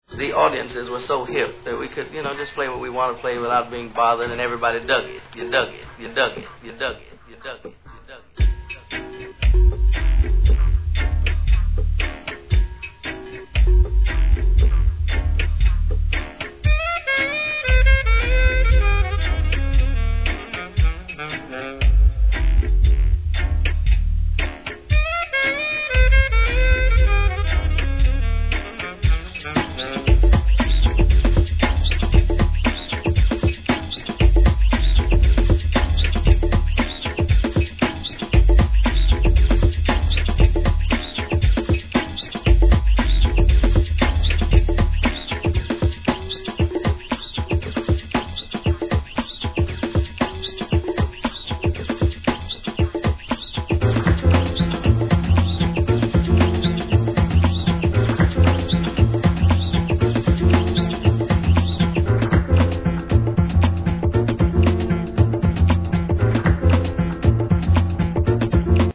HIP HOP/R&B
良質なJazzy Breakが満載!